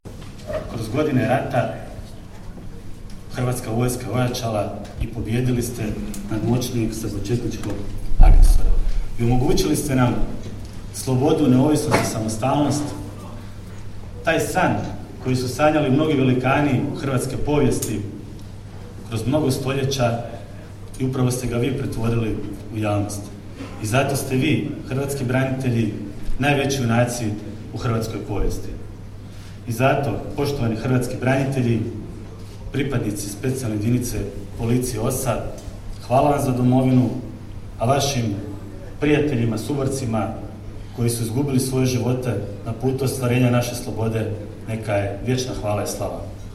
U srijedu, 15. ožujka 2023. godine, u Sisku u Hotelu Panonija svečano je obilježena 32. obljetnica osnutka Specijalne jedinice policije „OSA“.